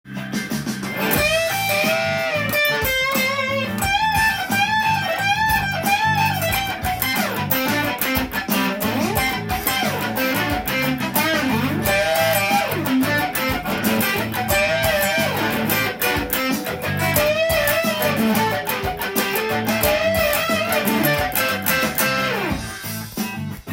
【カッティング奏法を入れたギターソロ】オリジナルtab譜
譜面通り弾いてみました
カッティング奏法を入れたギターソロを作ってみましたが
tab譜では「Amペンタトニックスケール」を使用しています。